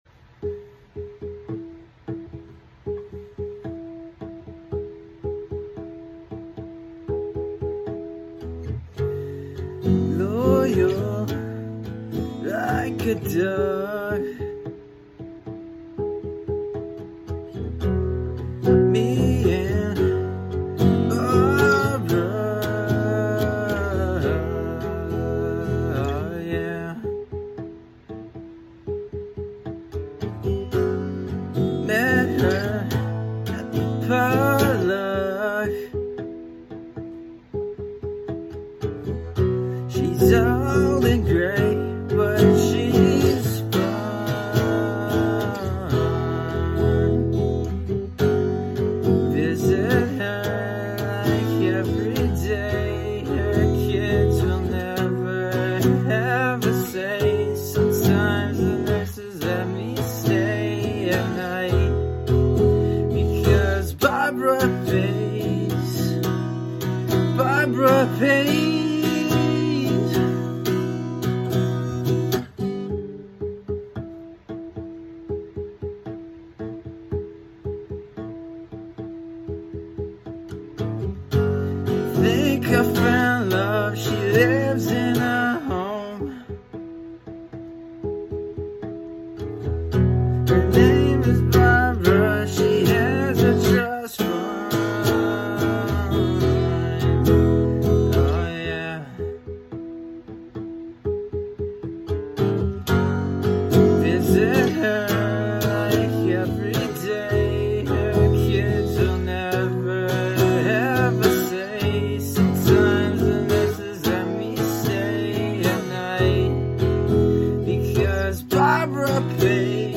original punk songs on accustic